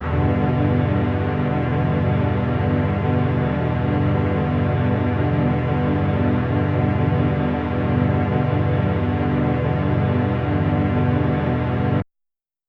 SO_KTron-Ensemble-Emin7.wav